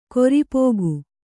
♪ koripōgu